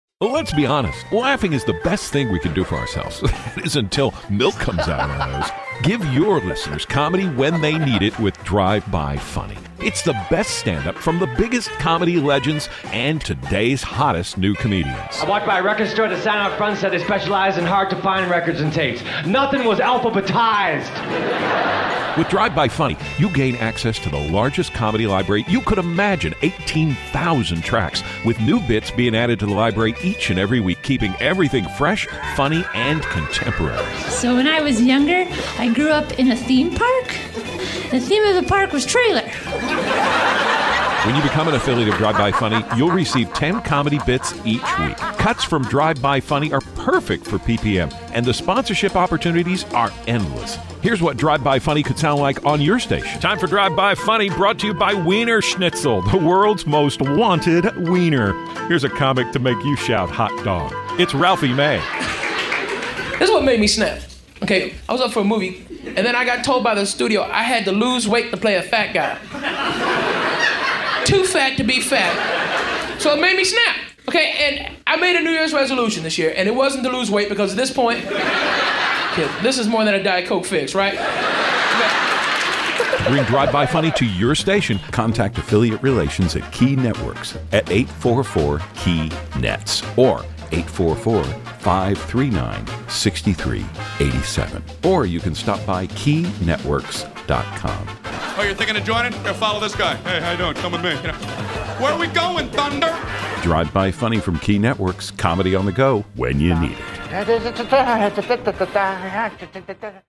A dozen comic gems from a wide variety of comics, all in a short-attention-span length of 60-90 seconds.